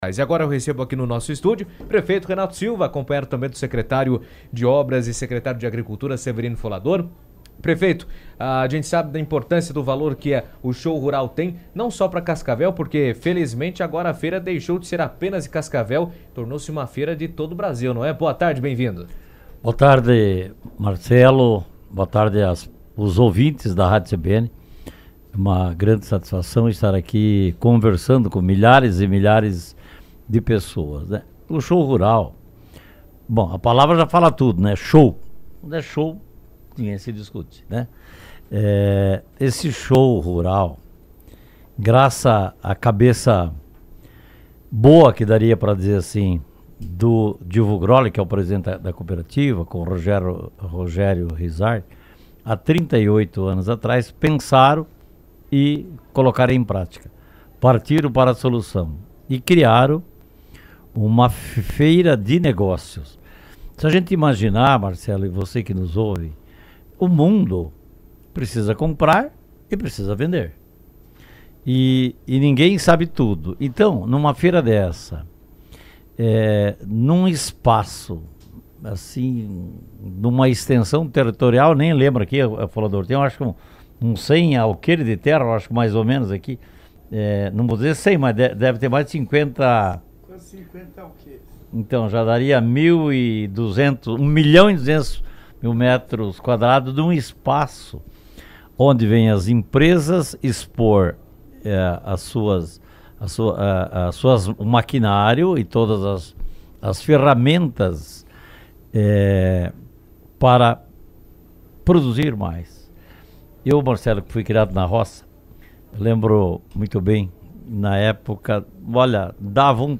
O prefeito Renato Silva esteve no estúdio da CBN durante a 38ª edição do Show Rural Coopavel e destacou a importância do evento para fortalecer o agronegócio local. Ele também comentou sobre o papel das políticas públicas municipais em melhorar a infraestrutura rural, incluindo obras e melhorias no campo, que contribuem para aumentar a produtividade, facilitar o escoamento da produção e gerar mais oportunidades para produtores e cooperativas da região.